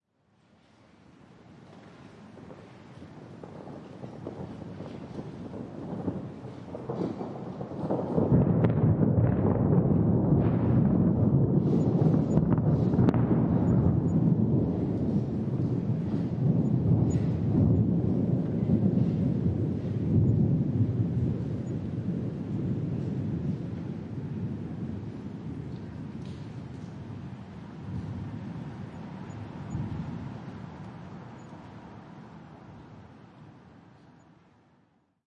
描述：雷霆鼓掌和雨。将Sennheiser MKH60 + MKH30转换为SD Mixpre3，使用免费的Voxengo插件解码为中端立体声
Tag: 风暴 气候 雷电 暴雨 雷暴 自然 现场录音